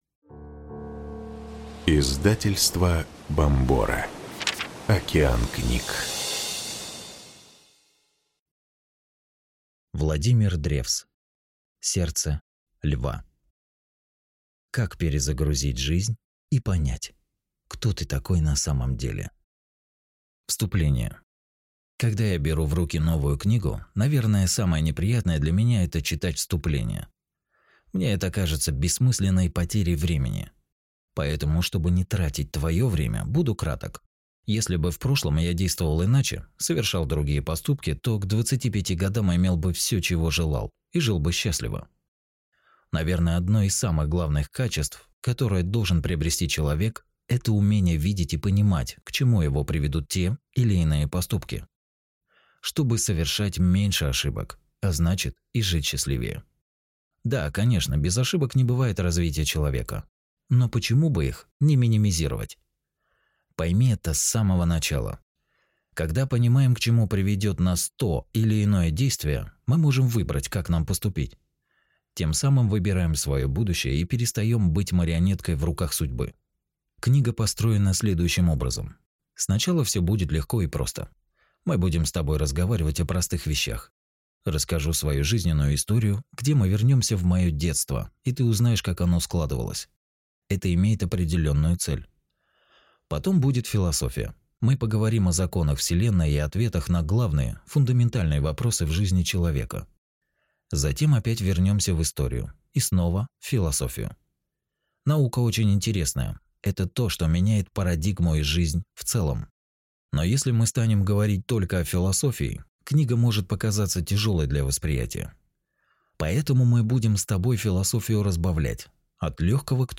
Аудиокнига Сердце Льва. Как перезагрузить жизнь и понять, кто ты такой на самом деле | Библиотека аудиокниг